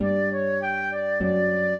flute-harp
minuet1-6.wav